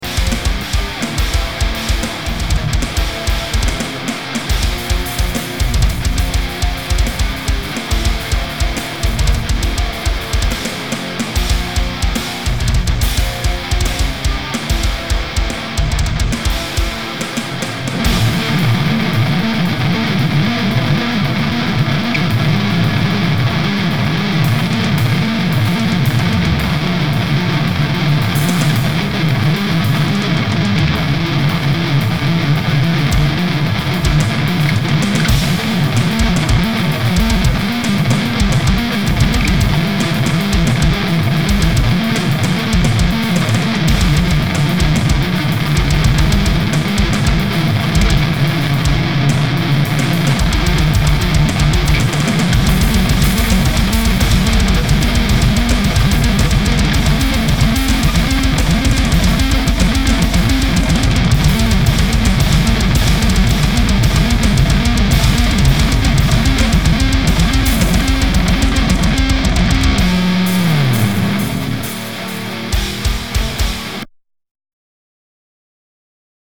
Recording Guitar: Sweeps
So im recording my band at home after spending too much money with little to show for it at a "studio" and the sound is turning out better than we had and for that it feels great! only one my guitarists has a moment in one of our songs that he does sweeps for about 40 seconds behind...
muddy sweeps im hoping this uploaded right... if it did this is a soundclip from the song with the "muddy" sweeps coming in momentarily...